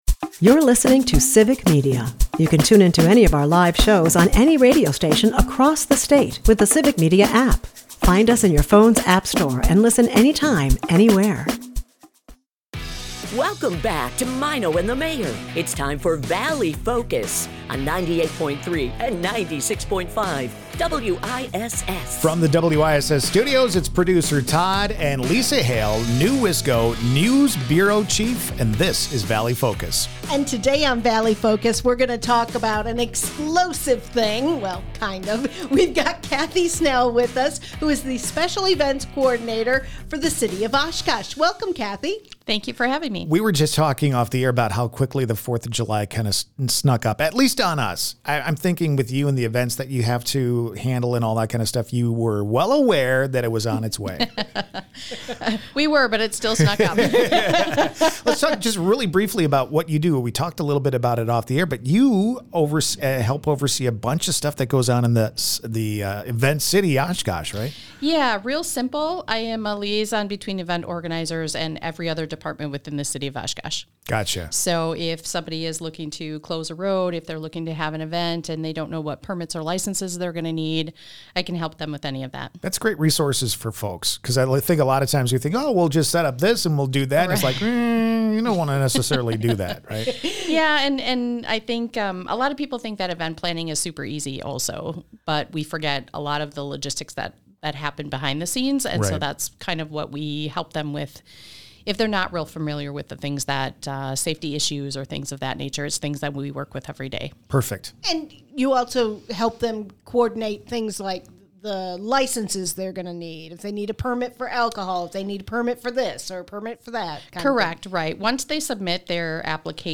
Valley Focus is a part of the Civic Media radio network and airs weekday mornings at 6:50 a.m. as part of the Maino and the Mayor Show on 96.5 and 98.3 WISS in Appleton and Oshkosh.